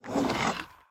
Minecraft Version Minecraft Version 25w18a Latest Release | Latest Snapshot 25w18a / assets / minecraft / sounds / mob / wither_skeleton / idle3.ogg Compare With Compare With Latest Release | Latest Snapshot